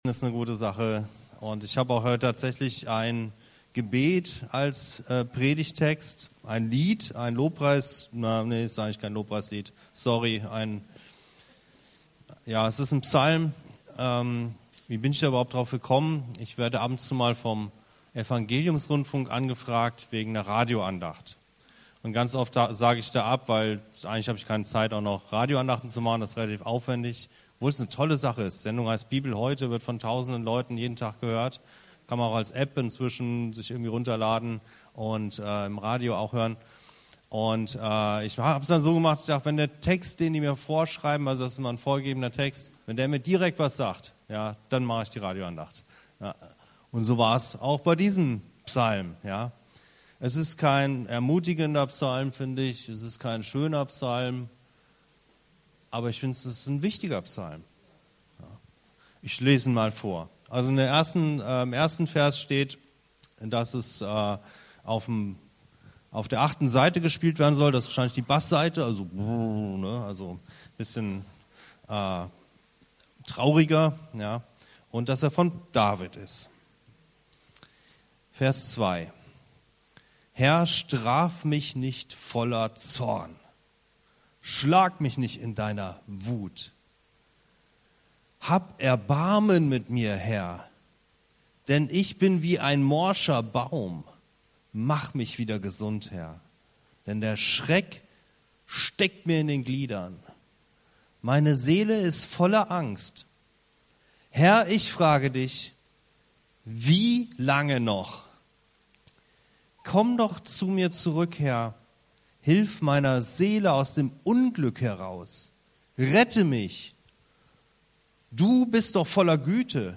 Wie lange noch? ~ Anskar-Kirche Hamburg- Predigten Podcast